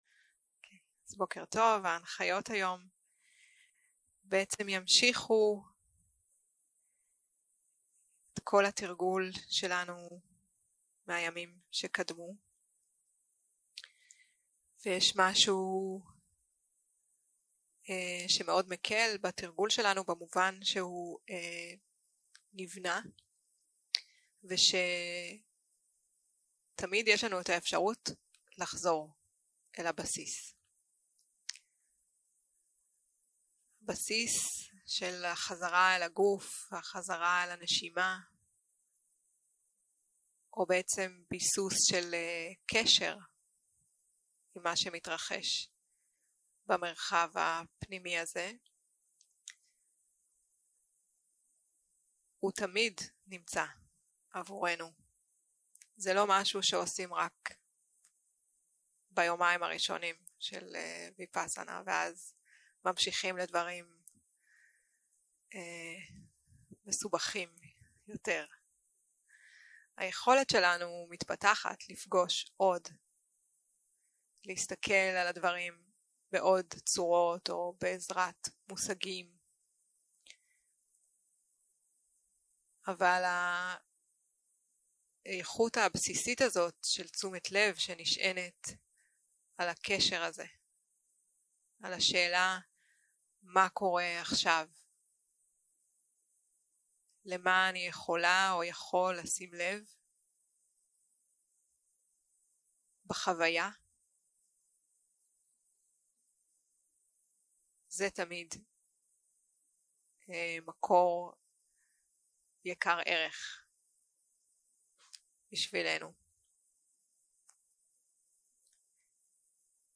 יום 5 - הקלטה 12 - בוקר - הנחיות למדיטציה